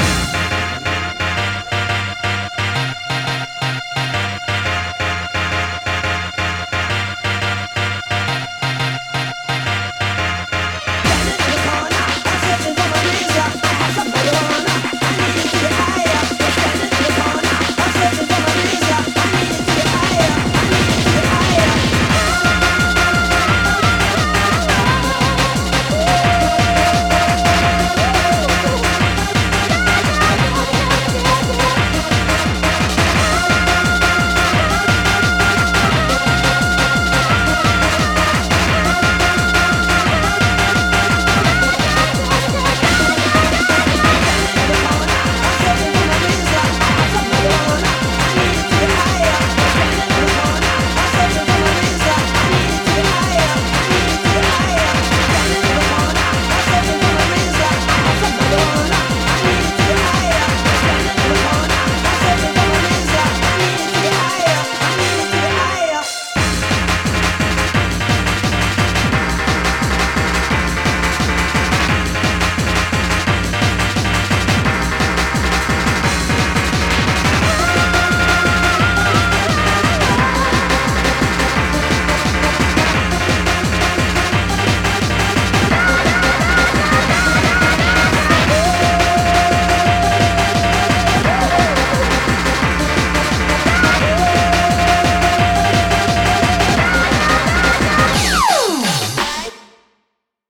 BPM174
Audio QualityPerfect (High Quality)
A really catchy song.